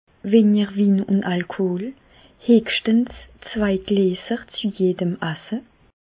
Bas Rhin
Ville Prononciation 67
Reichshoffen